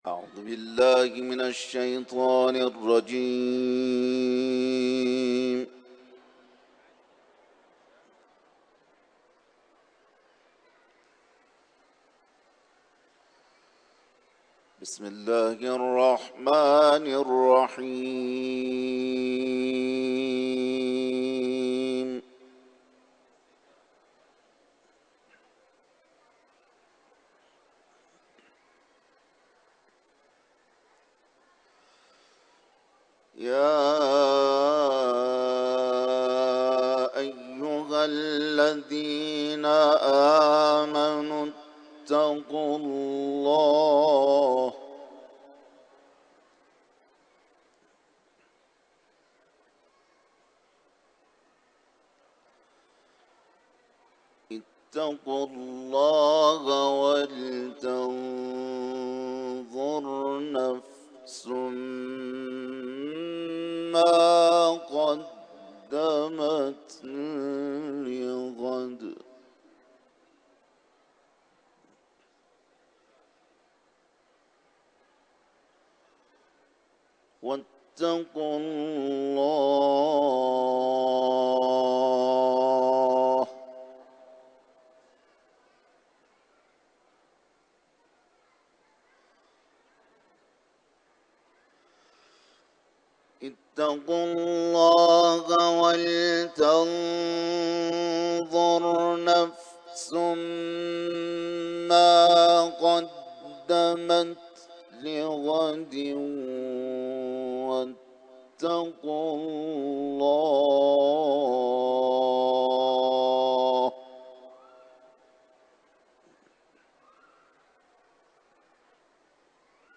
تلاوت